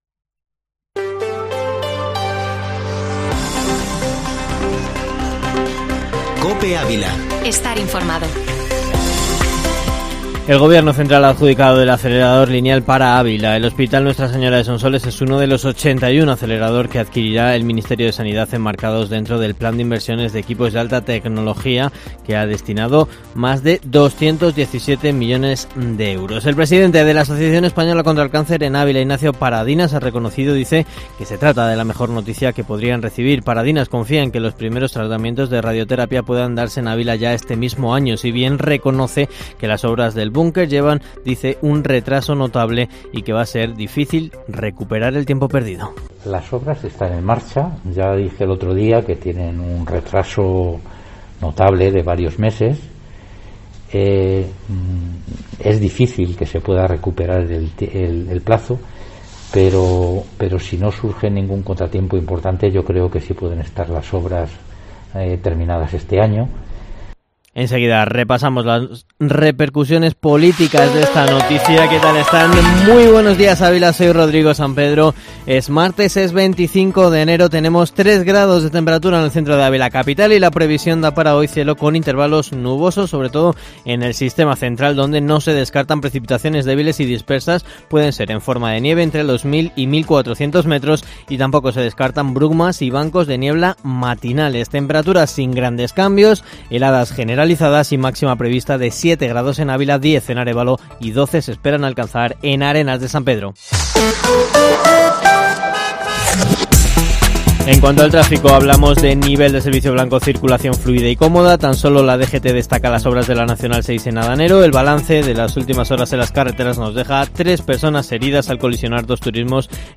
Informativo Matinal Herrera en COPE Ávila, información local y provincial